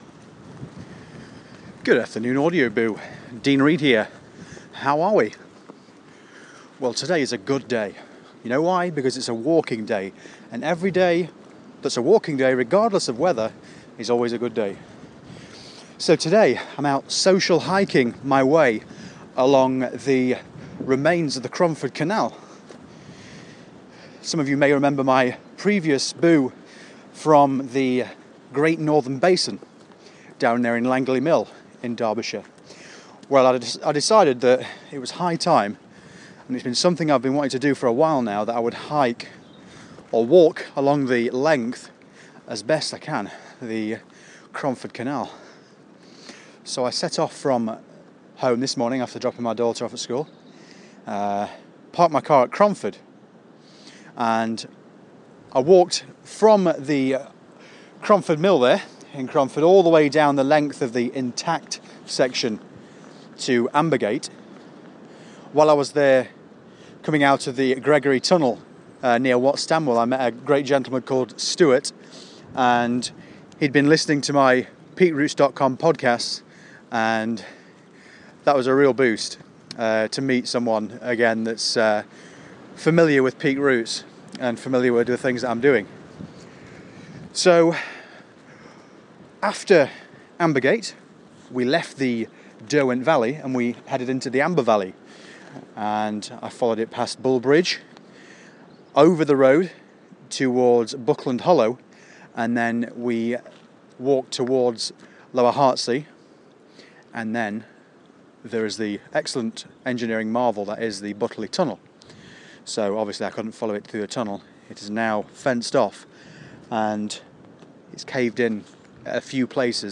The Cromford Canal - Walking from Cromford to Langley Mill